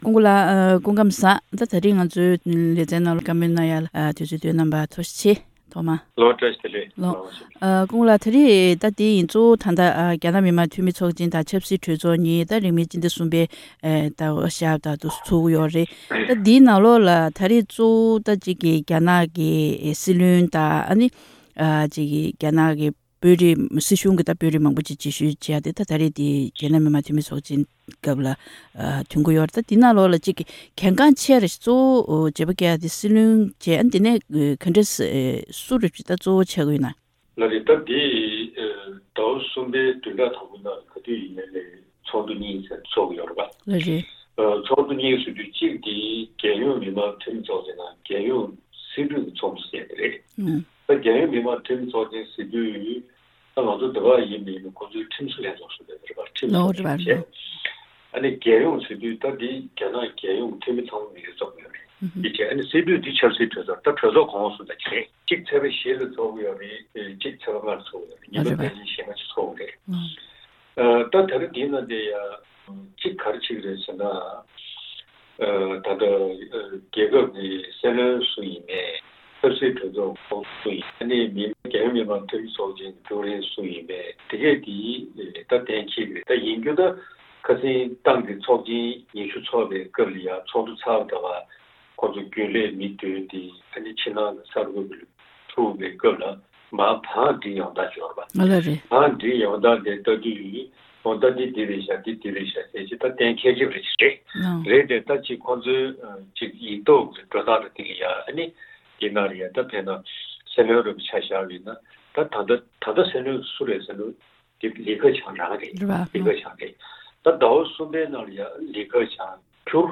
དམིགས་བསལ་བཅར་དྲིའི་ལེ་ཚན་ནང་།